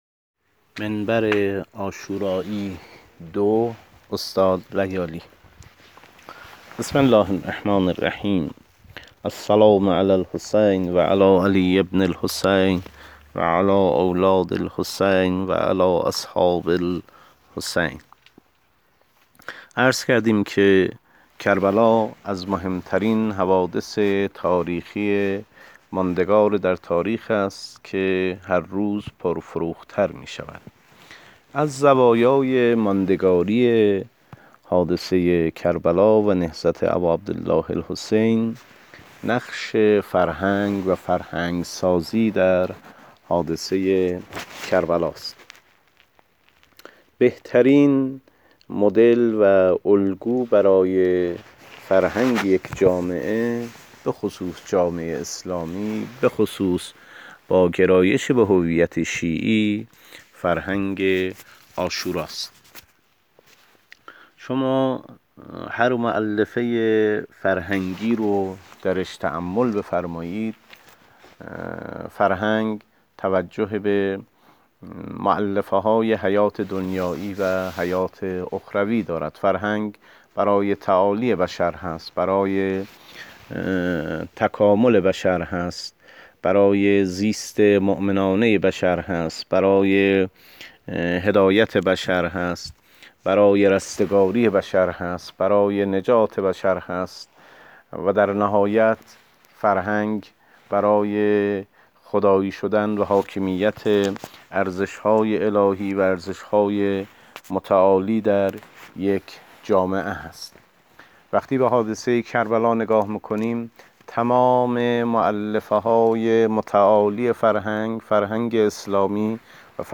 منبر عاشورایی(۲)؛